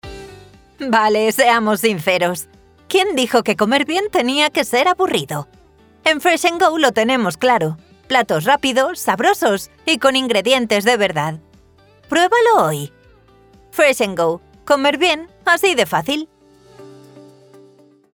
Grabaciones de Alta Calidad con Estudio Propio
Mi voz se encuentra en el rango de mujer adulta/joven y se adapta a diferentes estilos: cálida y cercana, sensual, agresiva, tímida, divertida… lo que necesites para tu proyecto.
Demo de publicidad voz alegre
Cabina insonorizada